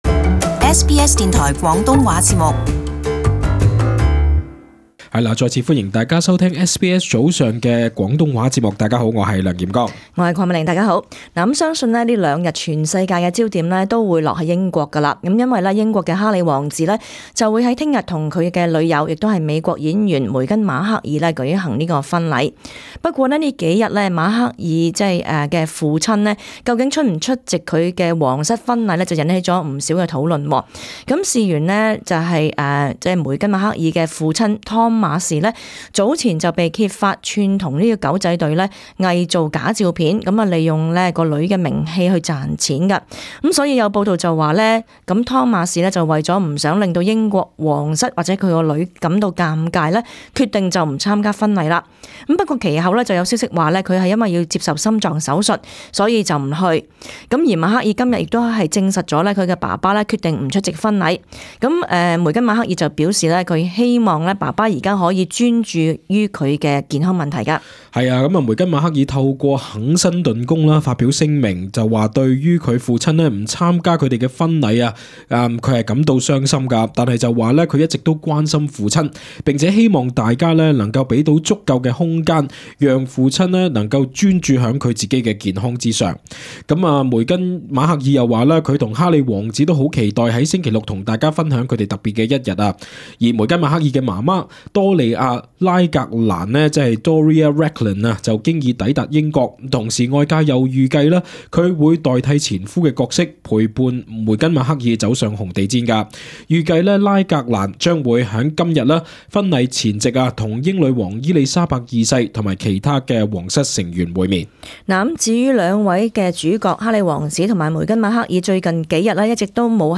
【時事報導】英國皇室婚禮